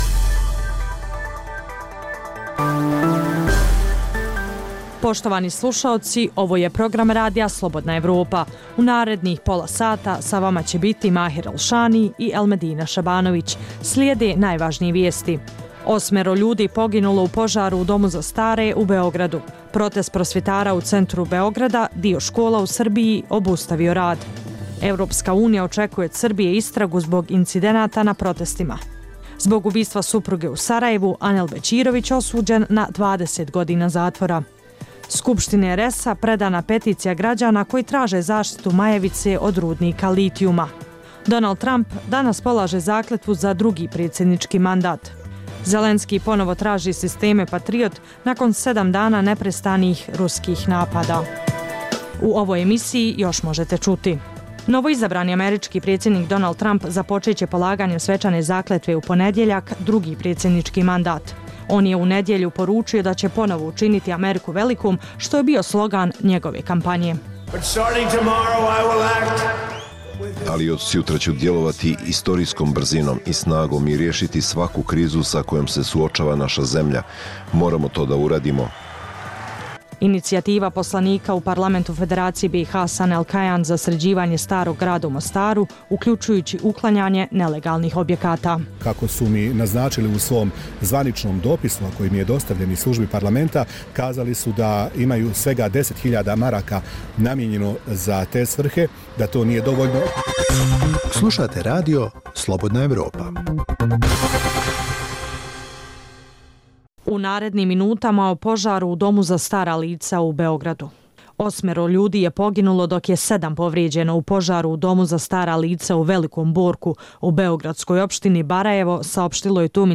Dnevna informativna emisija sa vijestima, temama, analizama i intervjuima o događajima u Bosni i Hercegovini, regionu i svijetu.